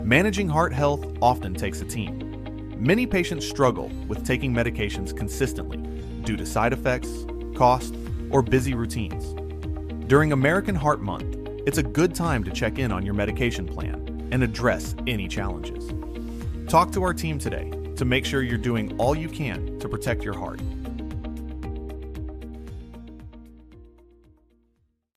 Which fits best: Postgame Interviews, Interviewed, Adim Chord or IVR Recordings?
IVR Recordings